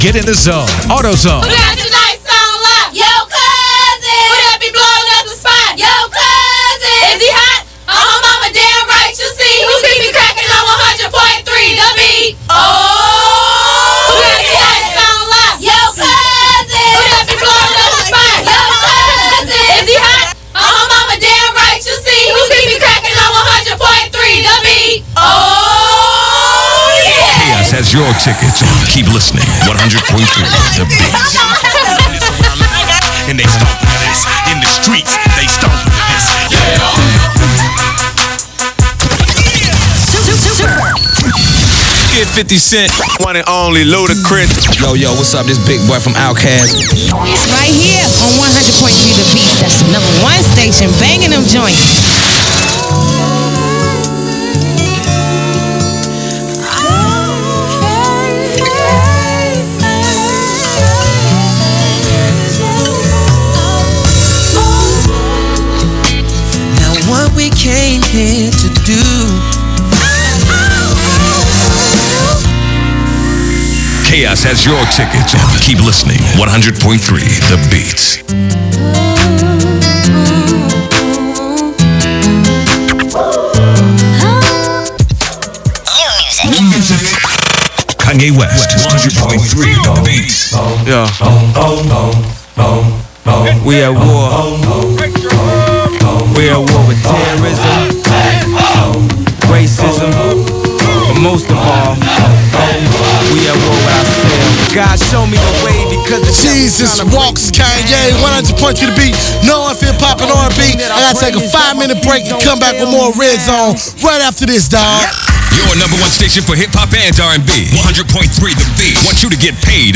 KATZ-FM Unknown announcer aircheck · St. Louis Media History Archive